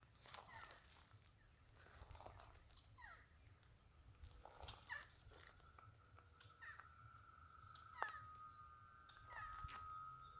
Carpintero Lomo Blanco (Campephilus leucopogon)
Nombre en inglés: Cream-backed Woodpecker
Fase de la vida: Adulto
Condición: Silvestre
Certeza: Observada, Vocalización Grabada
Carpintero-Lomo-Blanco.mp3